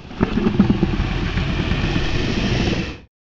rumble3.wav